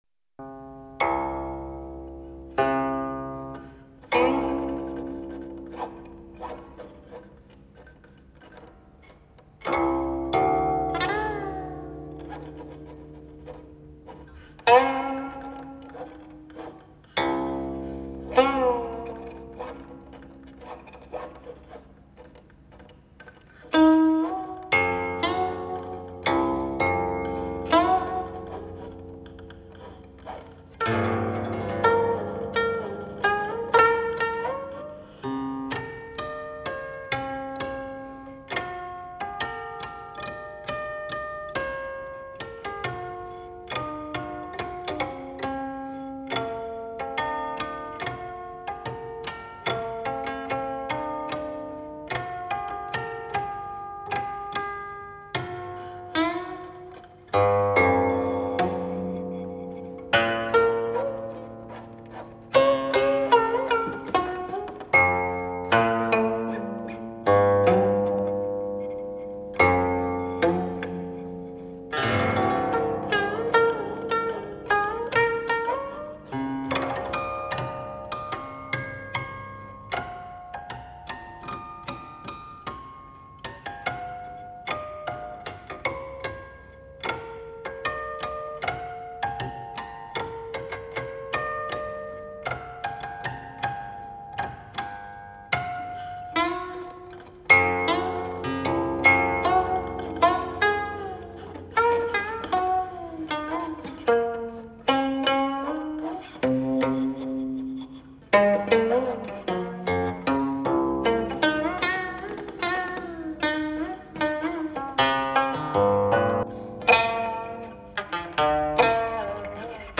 类型：中国传统音乐